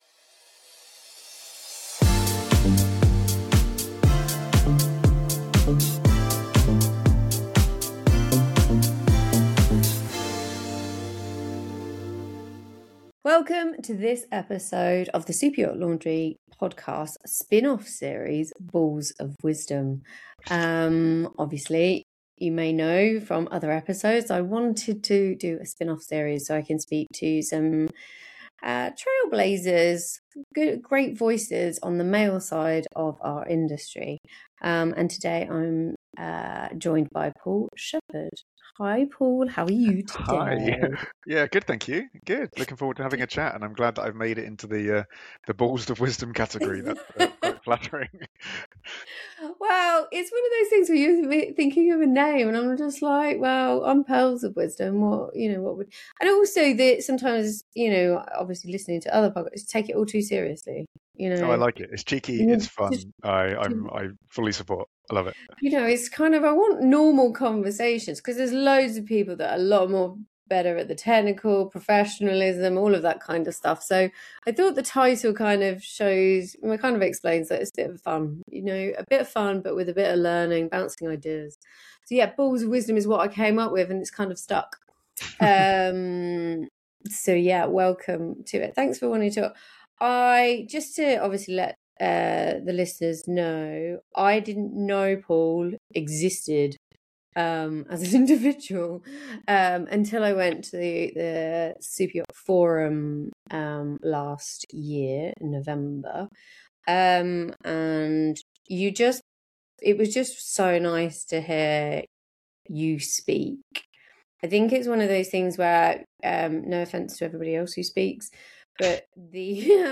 conversation about safety in the Superyacht industry, particularly within the often-overlooked interior department.